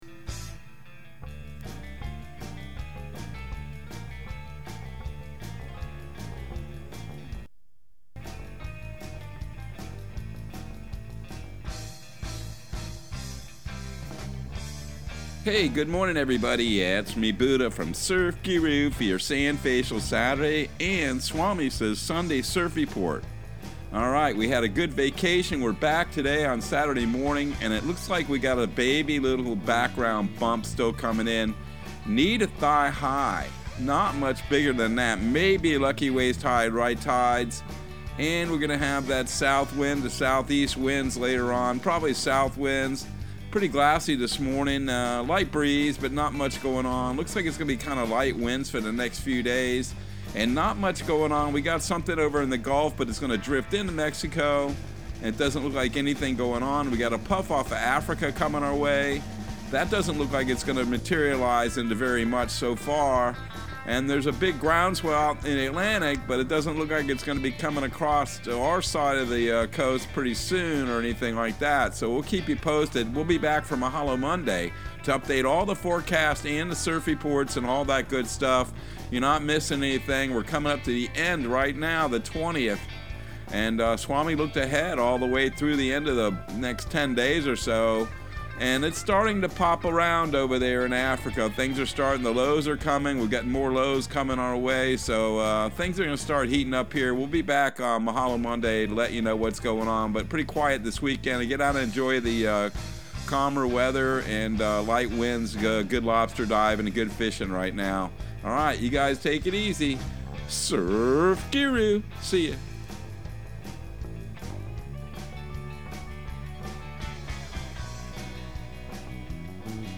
Surf Guru Surf Report and Forecast 08/20/2022 Audio surf report and surf forecast on August 20 for Central Florida and the Southeast.